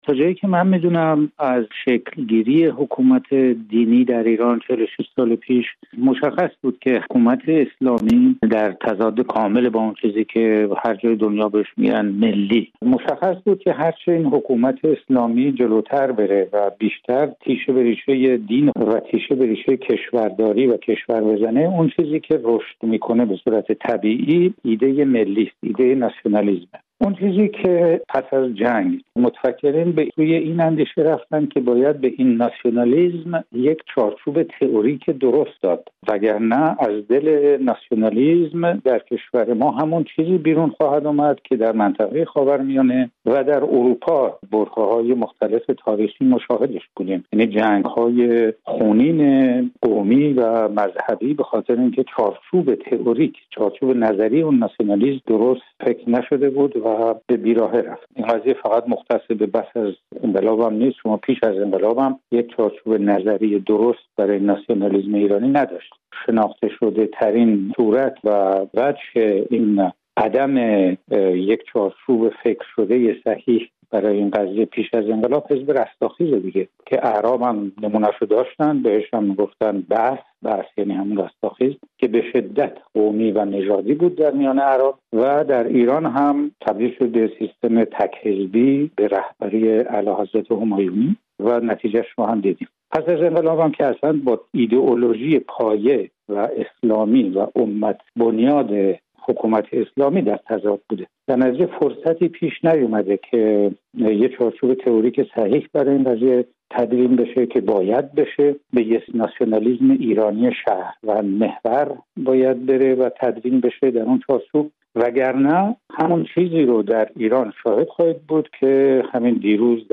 نویسنده و تحلیلگر ساکن فرانسه، گفت‌وگو کردیم.